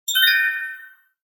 Neon Light Blinking Melody Sound Effect
A bright, futuristic melody sound effect inspired by blinking neon lights. Features crisp electronic tones with a rhythmic pulse, perfect for cyberpunk visuals, retro-futuristic scenes, UI animations, game menus, tech videos, intros, and digital transitions. High-quality, modern sound design with a vibrant neon vibe.
Neon-light-blinking-melody-sound-effect.mp3